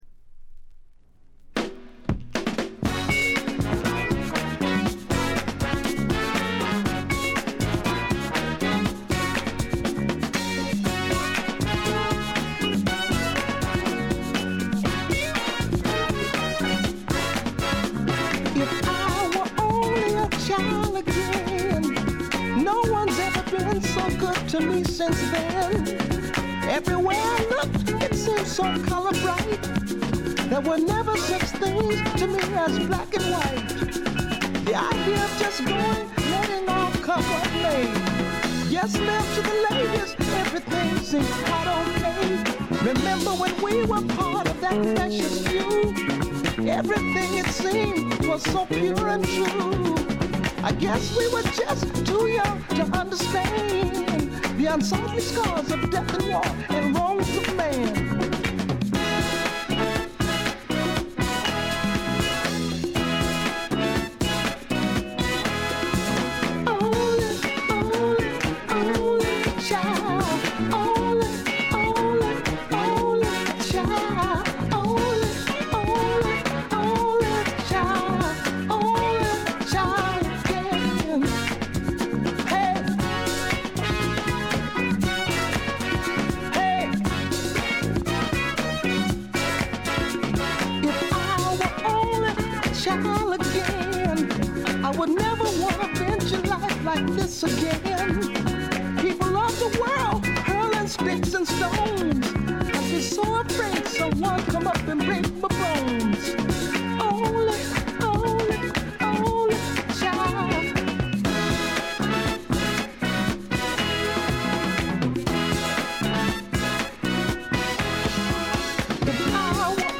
部分試聴ですがほとんどノイズ感無し。
試聴曲は現品からの取り込み音源です。